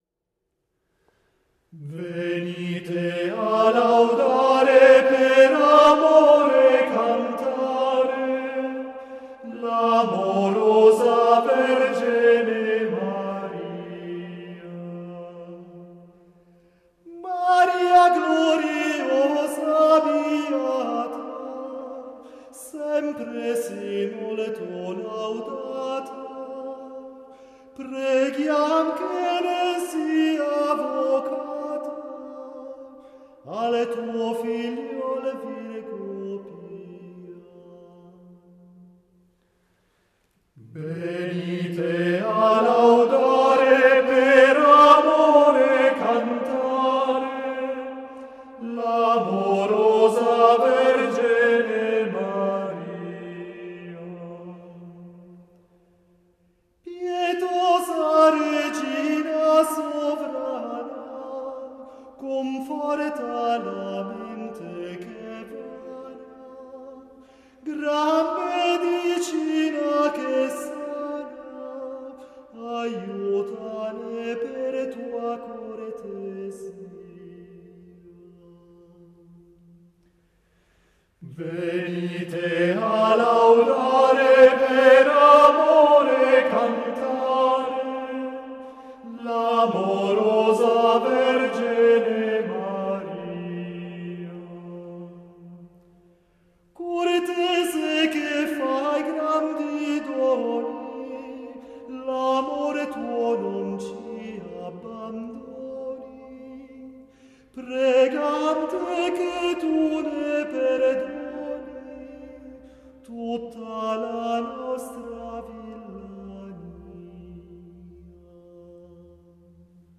Venite a laudare (esecuzione gruppo vocale Laurence Feininger)